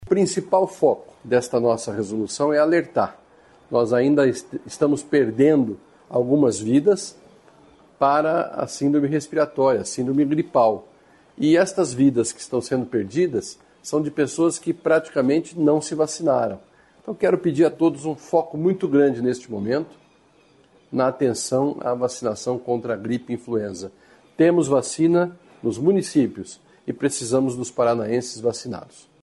Para a Agência Estadual de Notícias, o secretário de Saúde do Paraná, Beto Preto, falou sobre a resolução e fez um apelo para aumentar a cobertura vacinal contra a gripe.